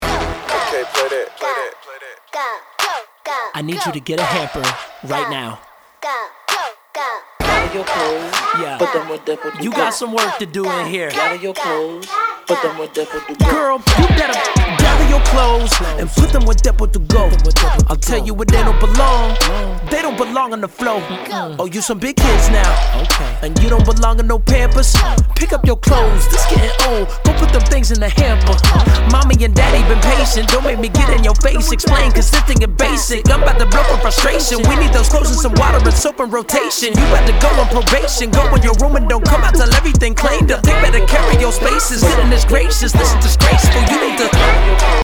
Hip Hop, soul and electronic